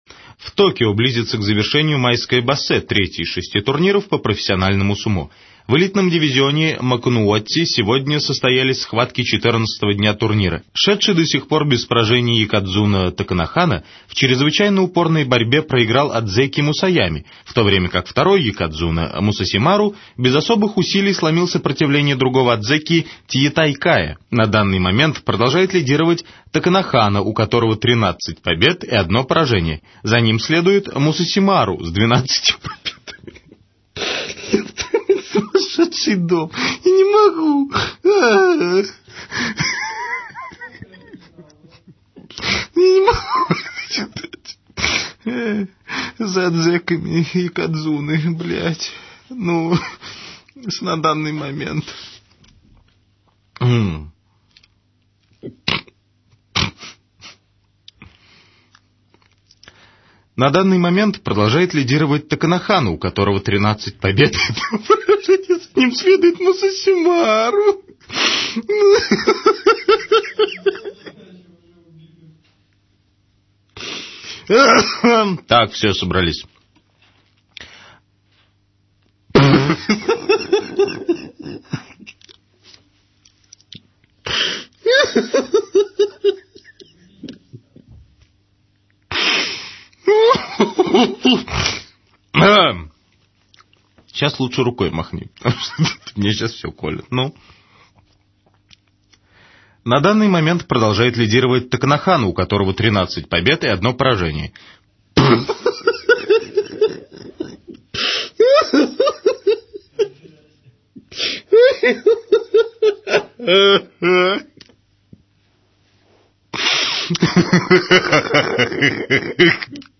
Мусасимару Неизвестное радио Угарная вещь. Комментатор то и время спотыкается на японских фамилиях.